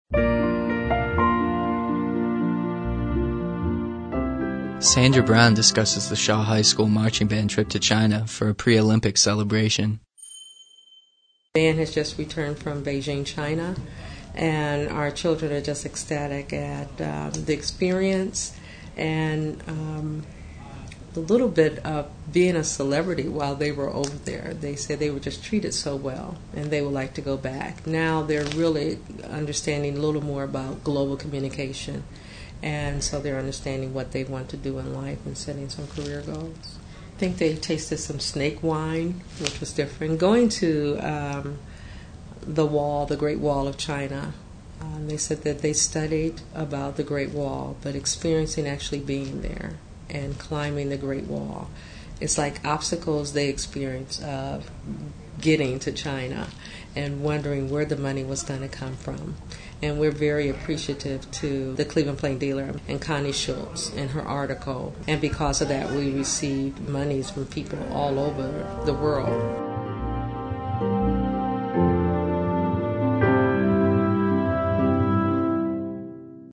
Source: Cleveland Regional Oral History Collection